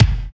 81 KICK 1.wav